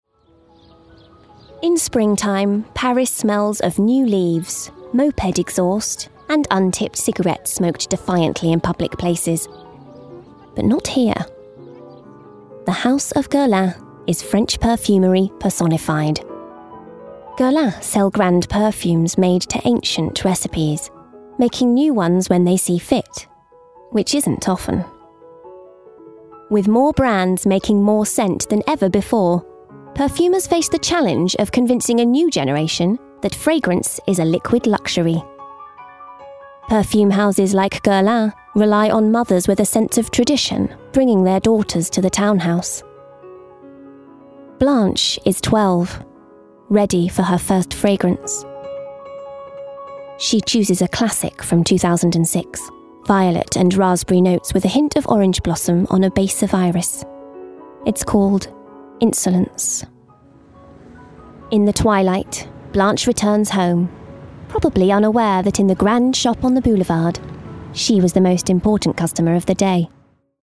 20/30's Neutral, Comedy/Quirky/Chatty
Perfume – Documentary